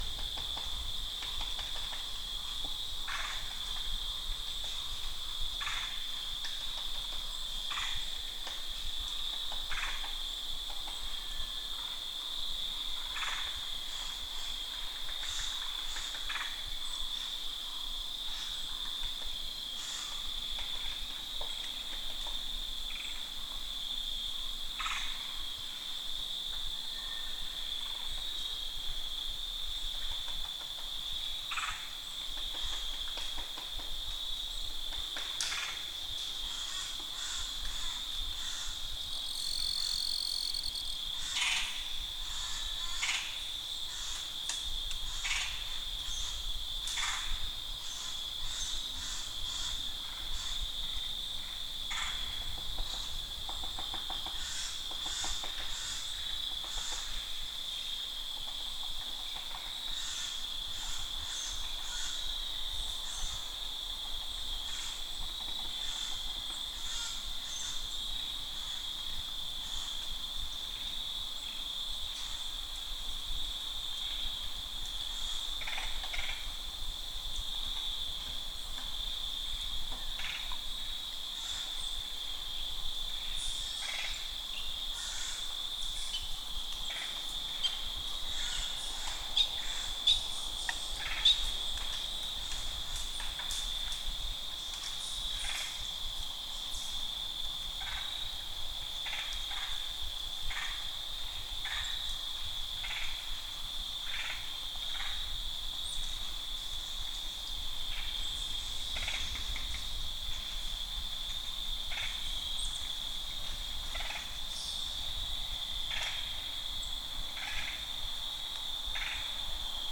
Afternoon Woods in October - Midwest US (loop) Sound Effect — Free Download | Funny Sound Effects